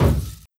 Kick (Surfin).wav